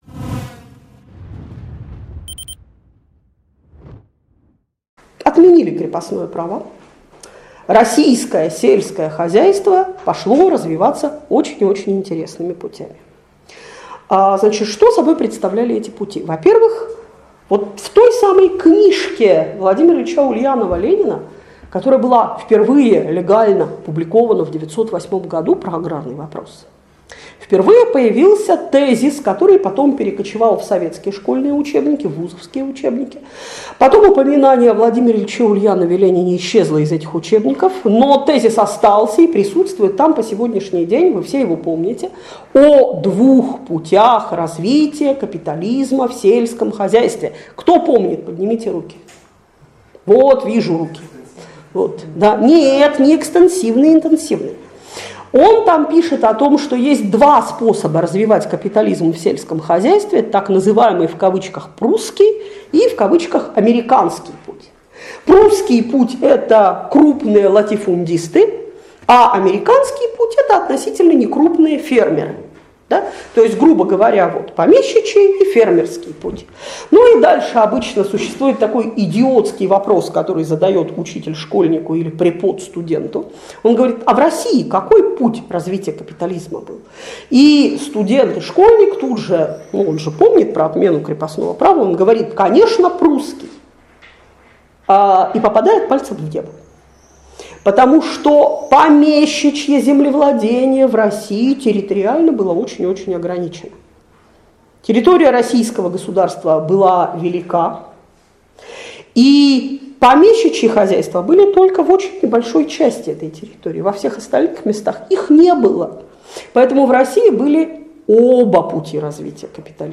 Лекция раскрывает особенности экономического развития и общественных связей в России на рубеже XIX-XX веков. Показывает экономические и социальные трансформации в России после отмены крепостного права.